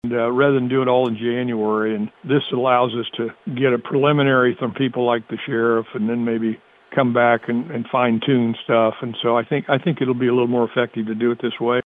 The review of the budget by the Livingston County Commission will take place as they prepare for the 2026 budget process.  Presiding Commissioner Ed Douglas says they began the process earlier this year.